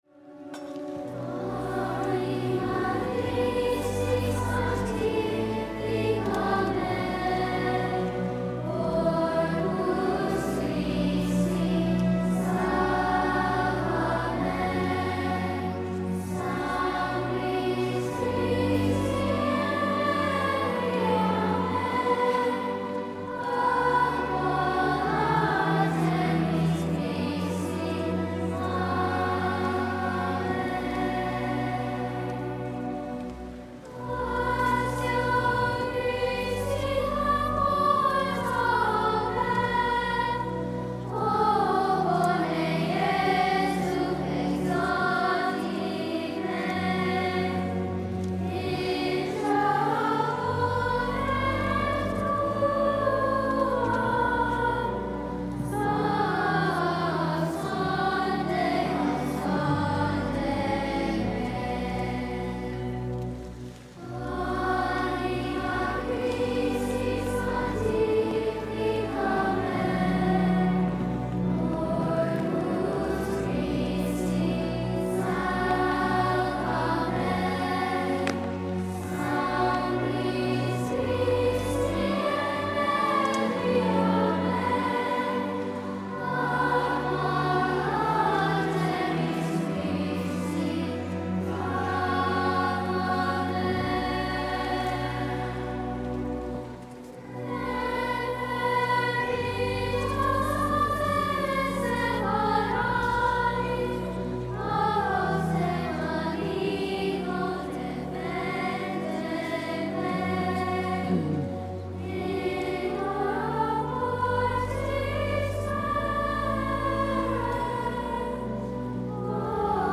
Mp3 Download • Live Children’s Choir Rec.
Ave Maria Parish Children’s Choir